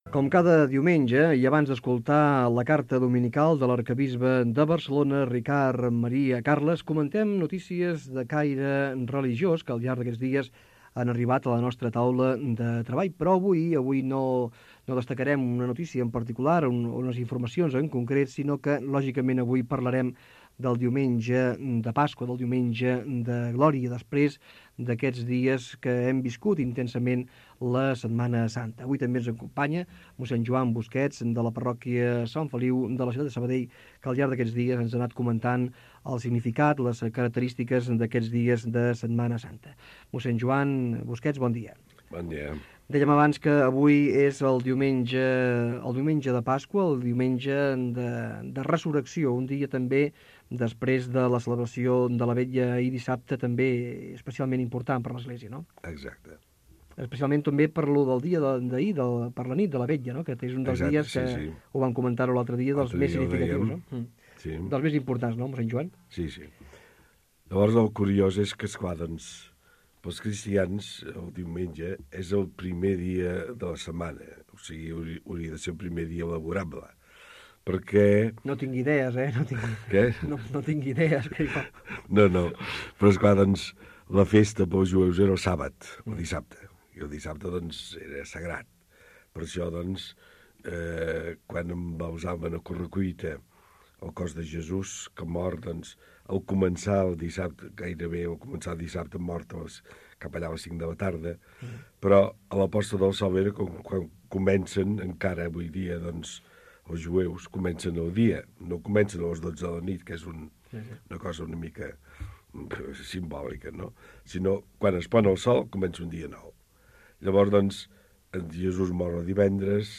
Religió